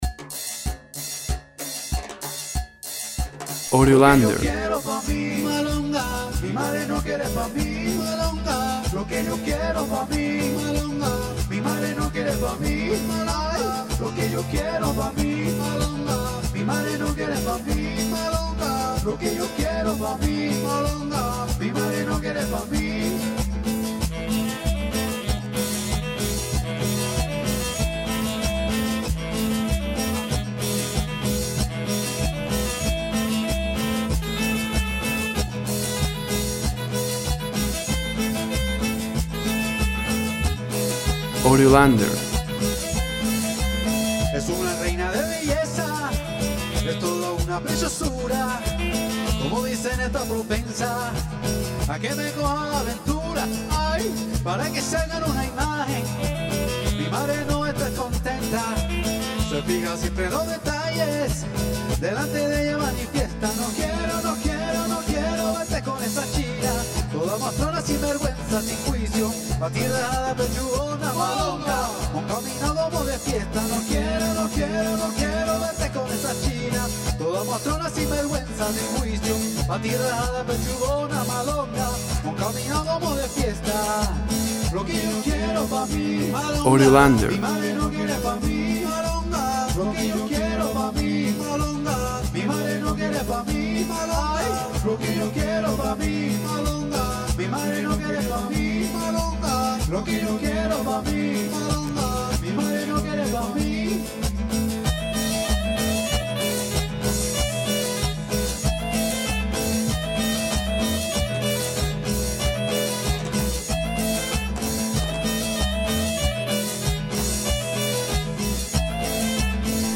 Traditional cumbia rhythm of Colombia.
WAV Sample Rate 16-Bit Stereo, 44.1 kHz
Tempo (BPM) 80